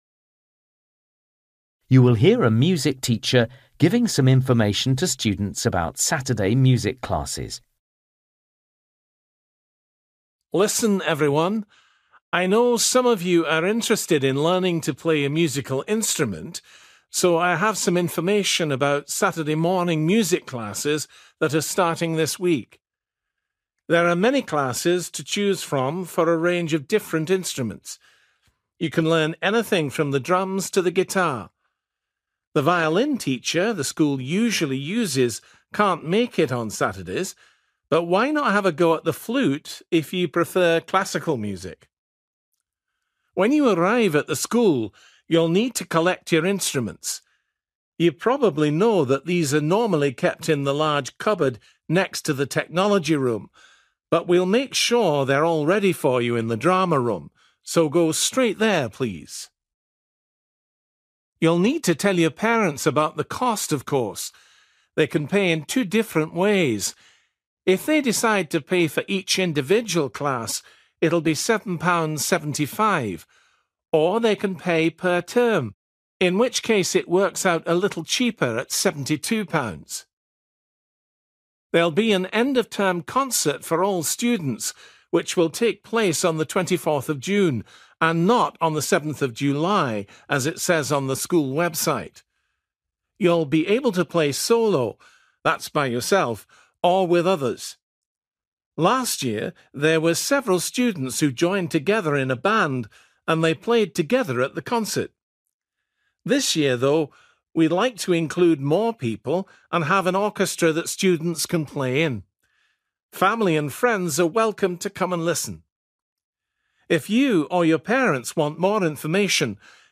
You will hear a music teacher giving some information to students about Saturday music classes.